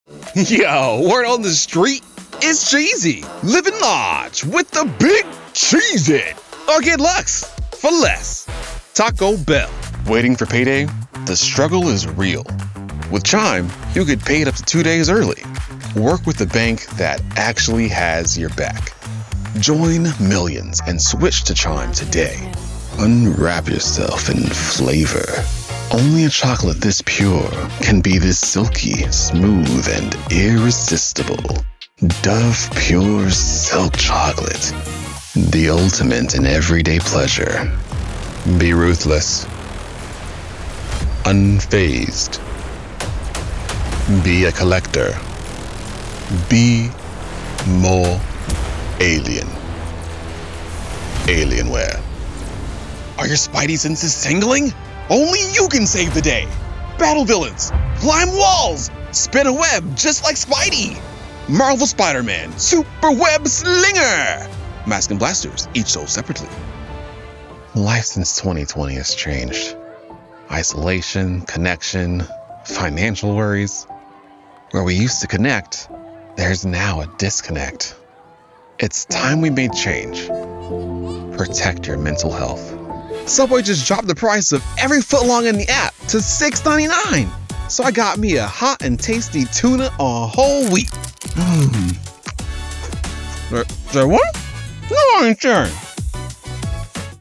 The Character Curator: Sultry, Smooth, Sophisticated Sounds.
Commercial Demo